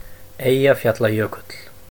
For example a transcription of something like the Icelandic name Eyjafjallajökull is pronounced [ˈeiːjaˌfjatl̥aˌjœːkʏtl̥]
Is-Eyjafjallajökull_(3).oga.mp3